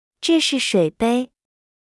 • Zhè shì shuǐbēi。